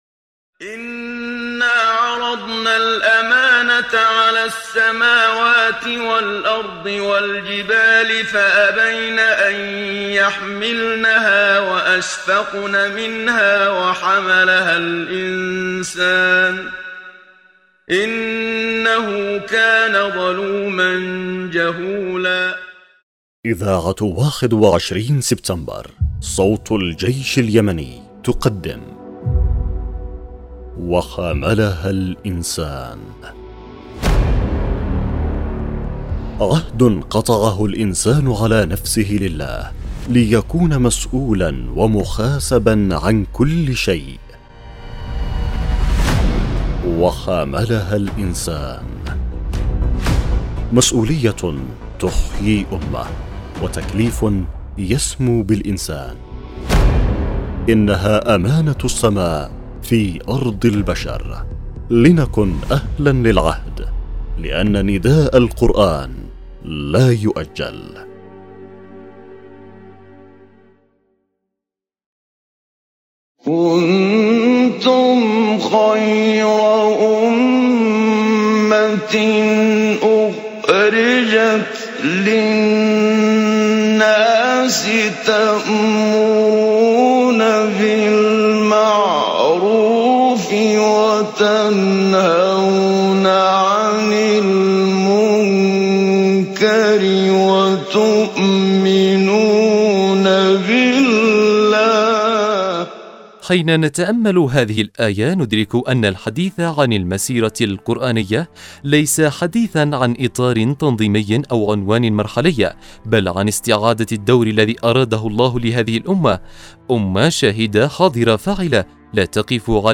برنامج إذاعي يعمل على مناقشة قضايا المسؤولية تجاه كل شيء المسؤولية تجاه الاسرة والأولاد وتجاه الدين والإسلام وتجاه المقدسات وتجاه الشهداء وكل قضية الانسان مسؤول عليها امام الله مع شرح ونقاش عن كل الجوانب التي تشملها تلك المسؤولية